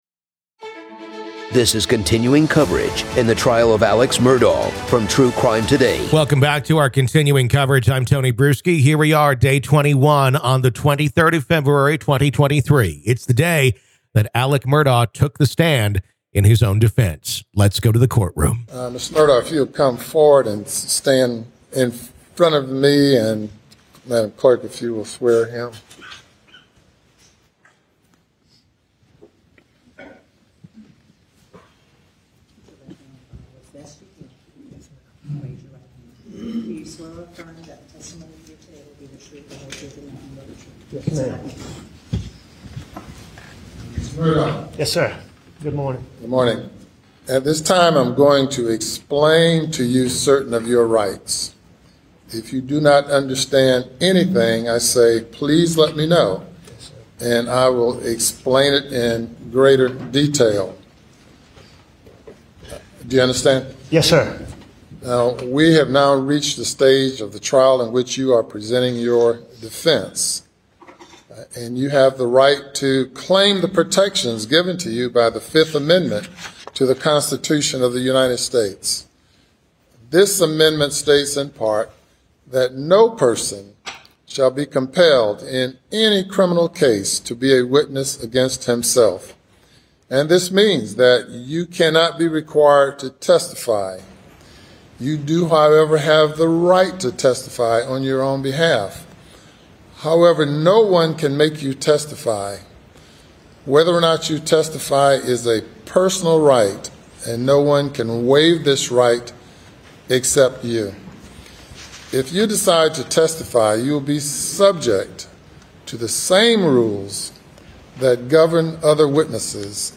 The Trial Of Alex Murdaugh | FULL TRIAL COVERAGE Day 21 - Part 1